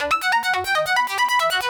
Index of /musicradar/shimmer-and-sparkle-samples/140bpm
SaS_Arp03_140-E.wav